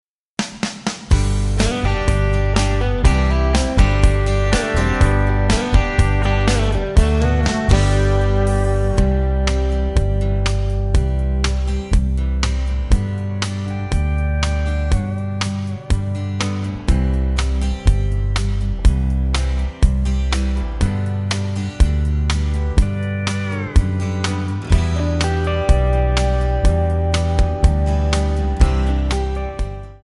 MPEG 1 Layer 3 (Stereo)
Backing track Karaoke
Country, 1990s